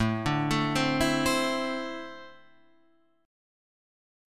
A4-3 chord